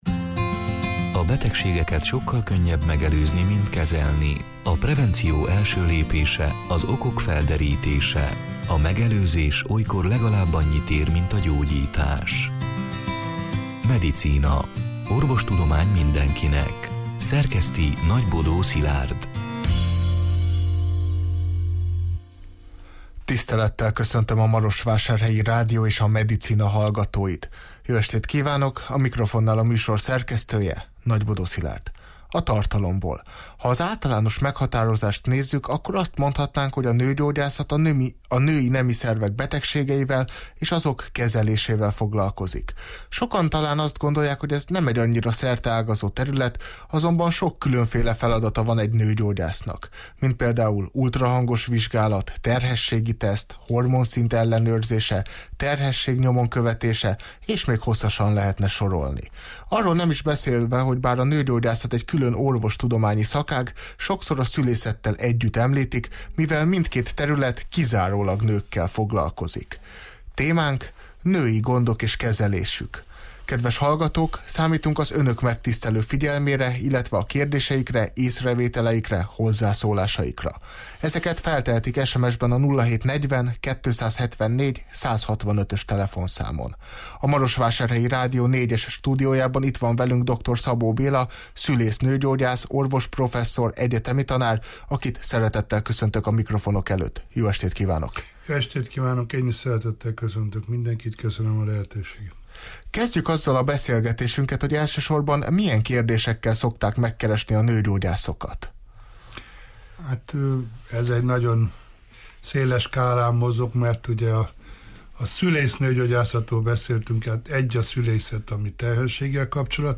A Marosvásárhelyi Rádió Medicina (elhangzott: 2022. október 19-én, szerda este nyolc órától élőben) c. műsorának hanganyaga: Ha az általános meghatározást nézzük, akkor azt mondhatnánk, hogy a nőgyógyászat a női nemi szervek betegségeivel és azok kezelésével foglalkozik.